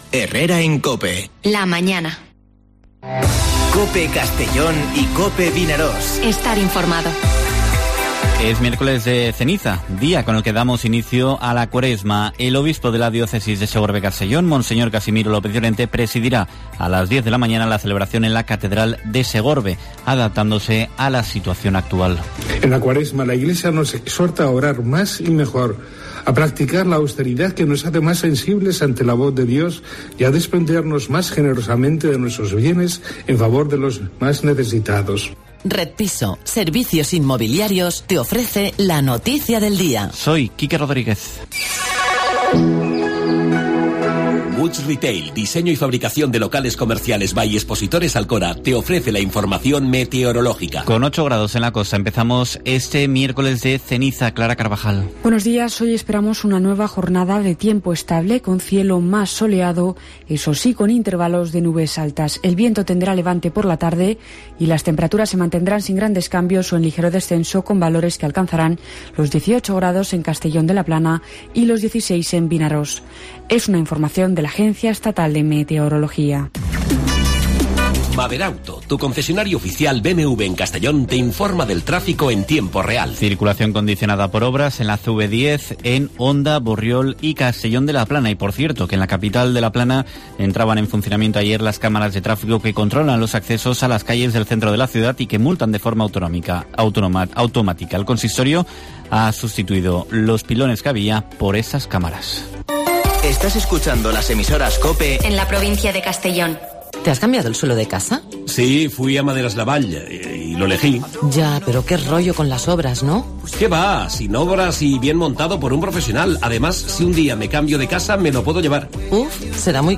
Informativo Herrera en COPE en la provincia de Castellón (17/02/2021)